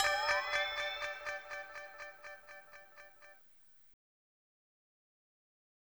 02C-FX-80E-L.wav